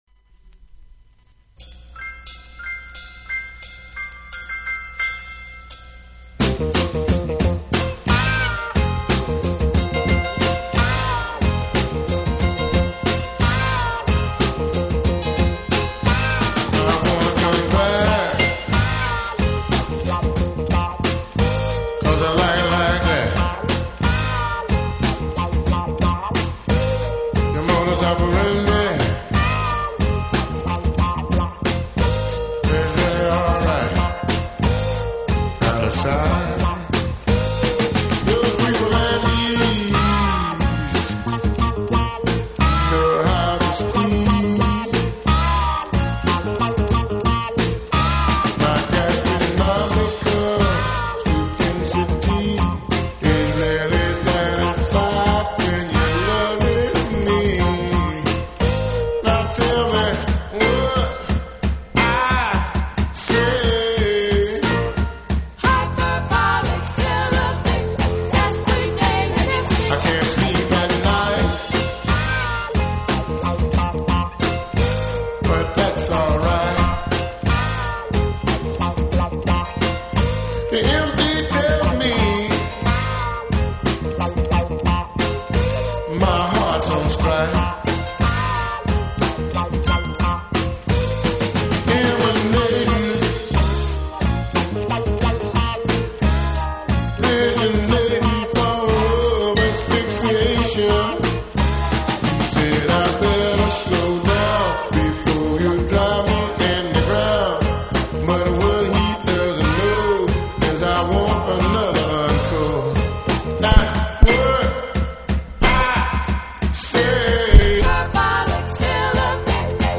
Immernoch mit ein paar technischen Problemen, aber immerhin ging das Mikro! Ein paar "deepfunk"-Klassiker und ein bißchen neues, checkt es aus!